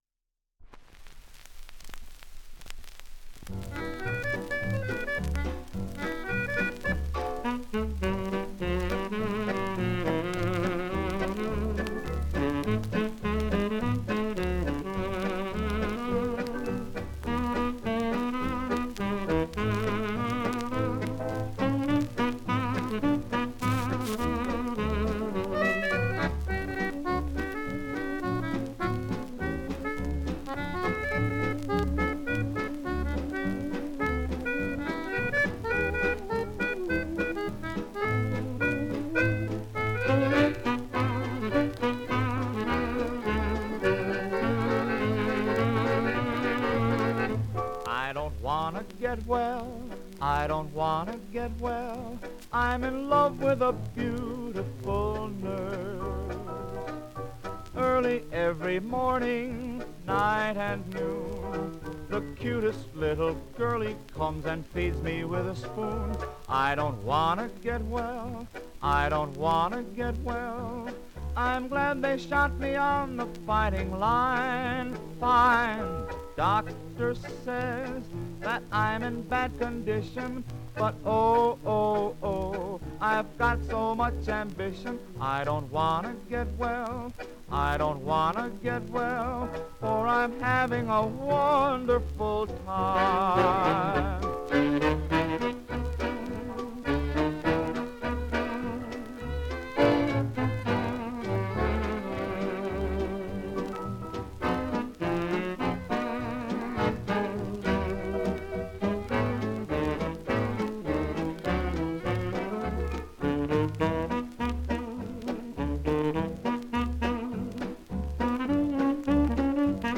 Genre: Medley.